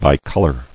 [bi·col·or]